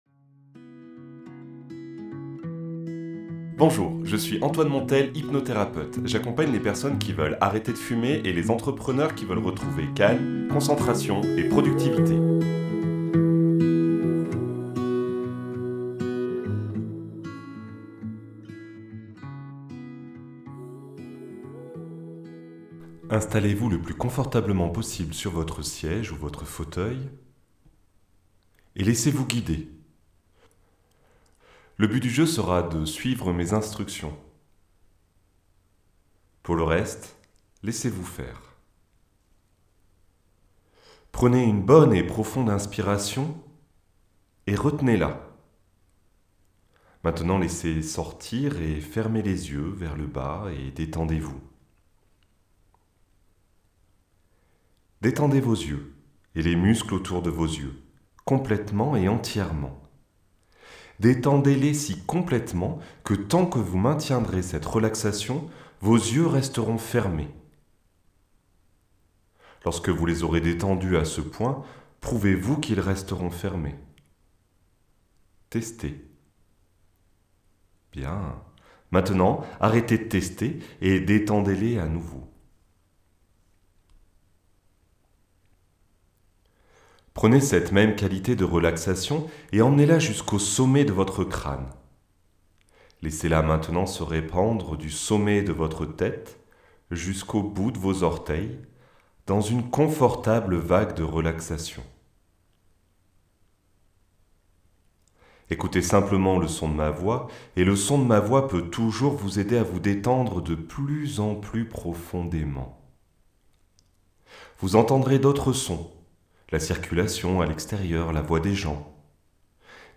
Lien pour un audio d'auto-hypnose